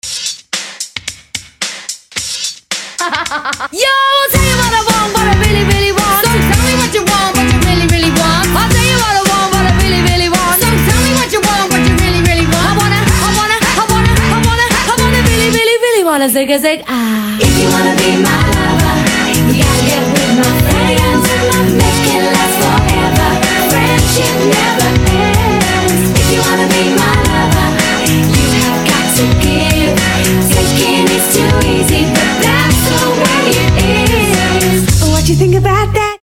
• Качество: 320, Stereo
поп
Хип-хоп
dance